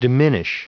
Prononciation du mot diminish en anglais (fichier audio)
Prononciation du mot : diminish